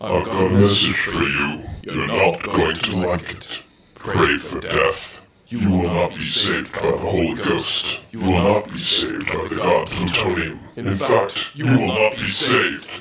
a sinister voice speaks and you hear